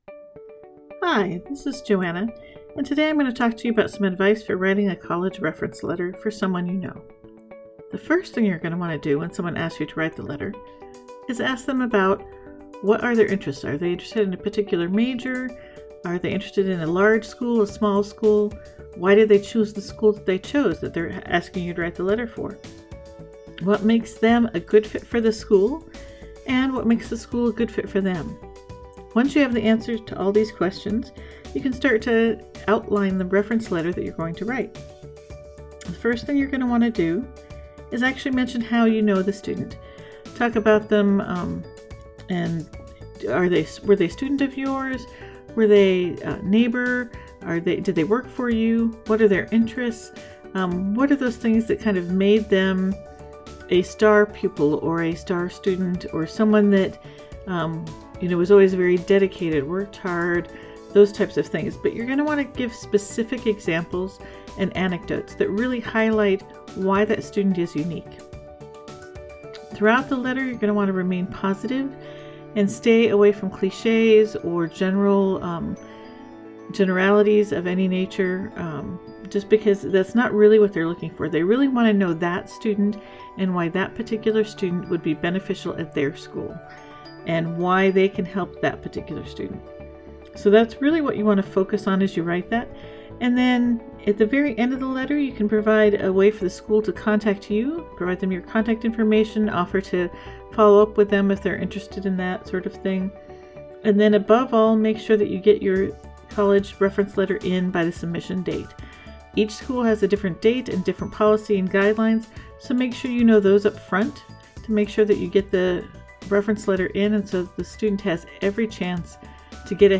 I added music from Bensound to engage listeners and to increase retention.
writing-a-college-reference-letter-podcast.mp3